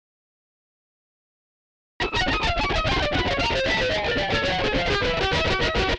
Glassy Scrape
Rather than dragging the pick along the strings, scrape it across the surface of the strings almost as though you were strumming them. It creates an interesting “glassy” kind of sound from the strings.
GlassyScrape.mp3